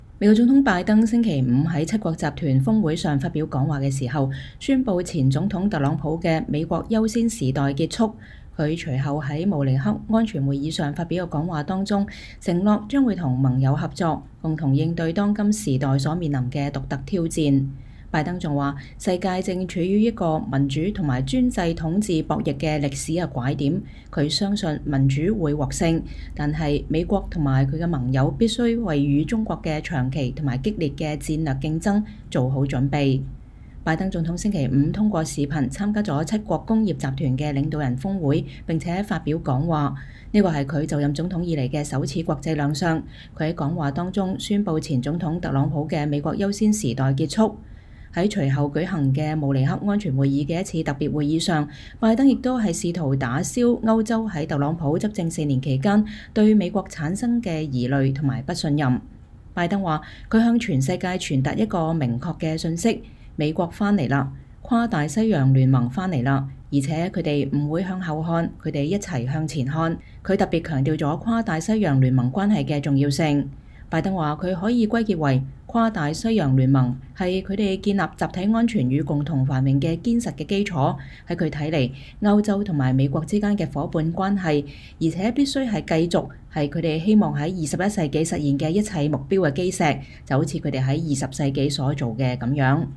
美國總統拜登在白宮通過視頻在慕尼黑安全會議上發表講話。 （2021年2月19日）